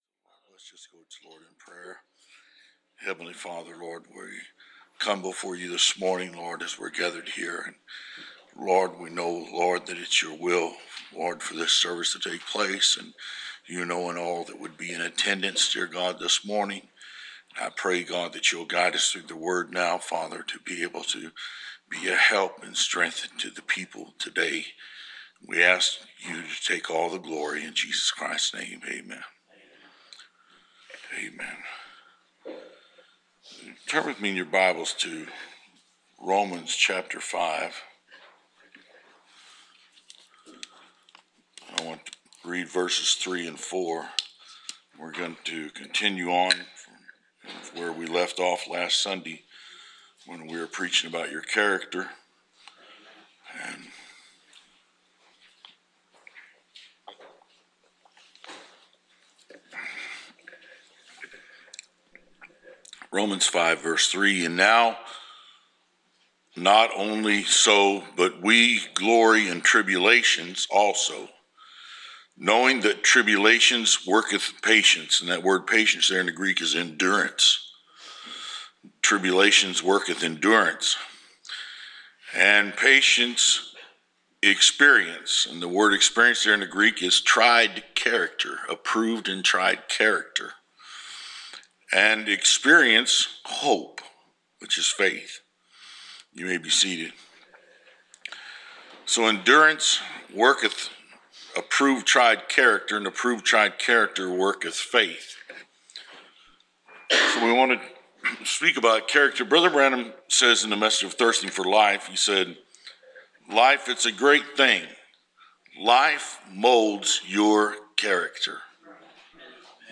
Preached June 6th, 2021